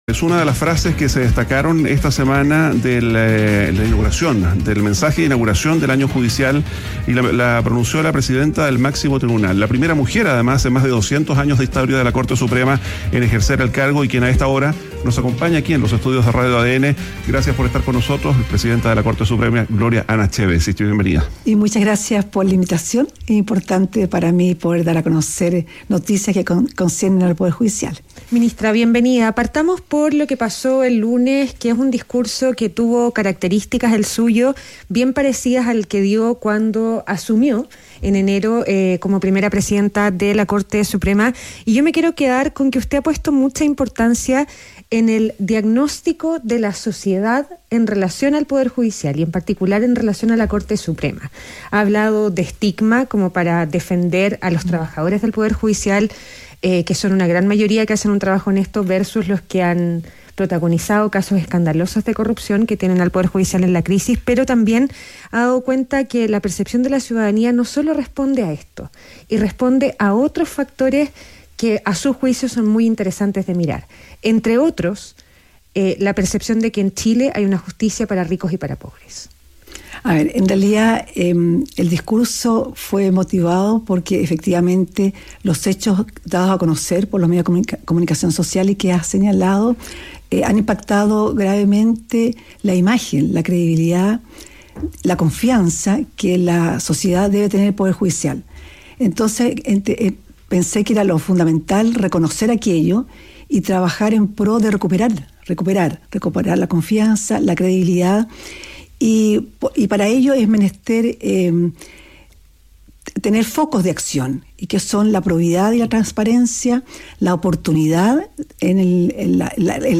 En conversación con ADN Hoy, la presidenta de la Corte Suprema reconoció la crisis de confianza que atraviesa el Poder Judicial tras los casos de corrupción conocidos en los últimos meses y aseguró que el tribunal trabaja en medidas para recuperar la credibilidad de la institución.